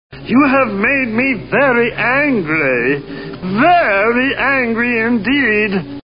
PLAY Marvin Very Angry
marvin-angry-very-angry.mp3